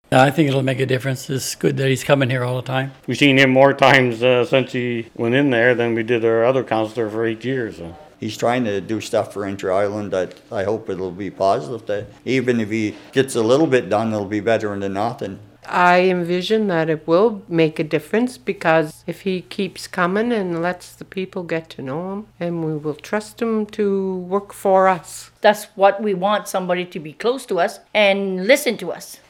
C’est ce qui ressort d’un vox-pop réalisé samedi dernier dans la petite communauté anglophone, alors qu’une dizaine de personnes s’étaient déplacées au centre multifonctionnel pour échanger avec l’élu.